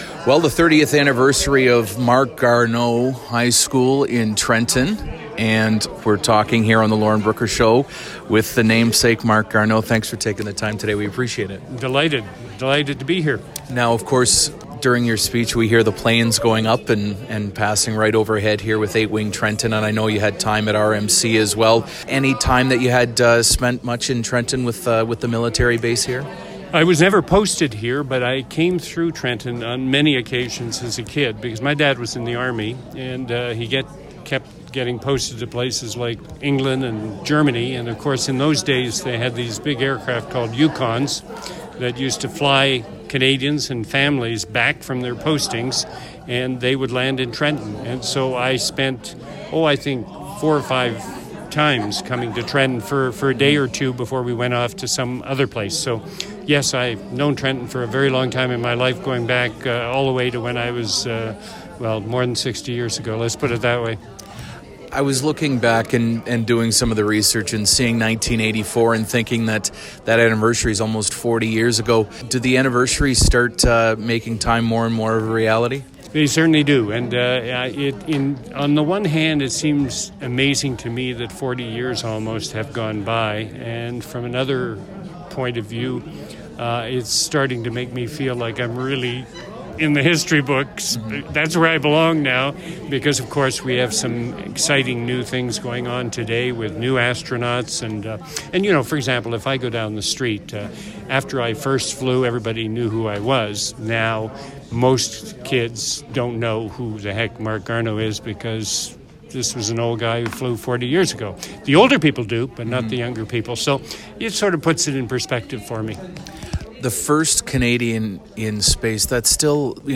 Garneau’s full interview with Quinte News can be heard below.